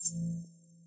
warning_pulse.mp3